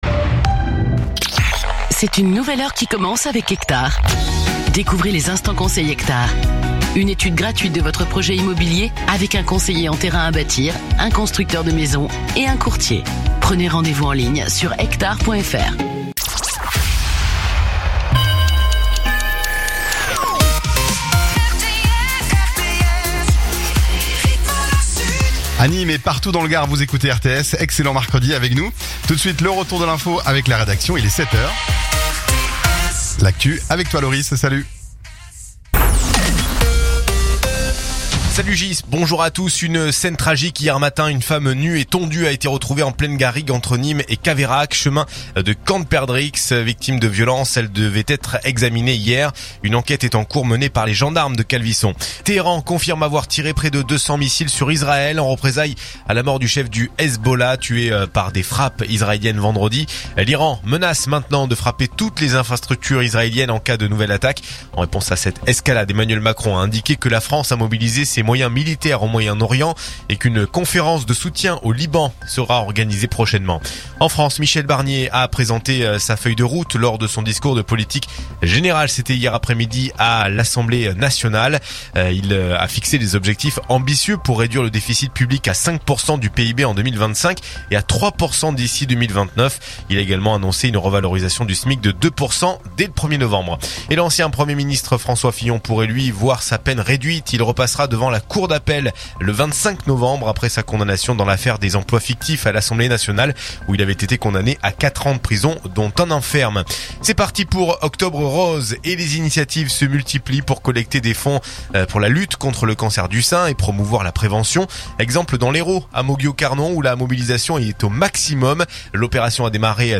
Écoutez les dernières actus de Nîmes en 3 min : faits divers, économie, politique, sport, météo. 7h,7h30,8h,8h30,9h,17h,18h,19h.